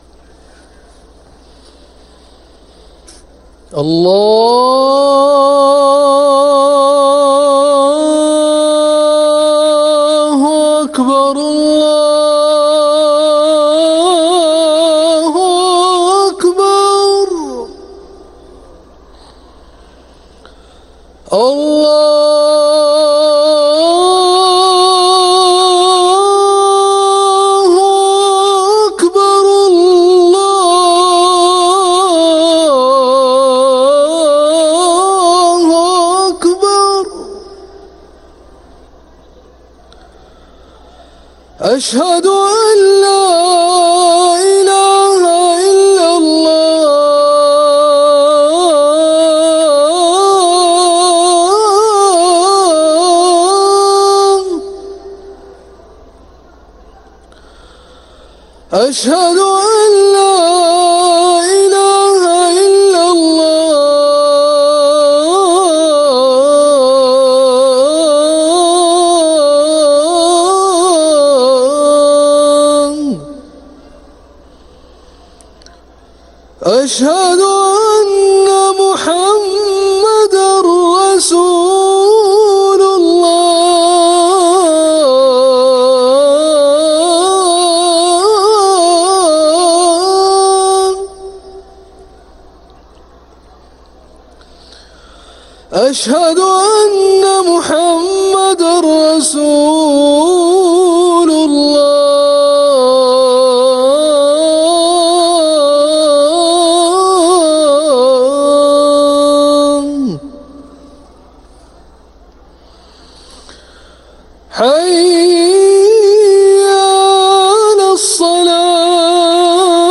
محاكياً الشيخ علي ملا أذان العشاء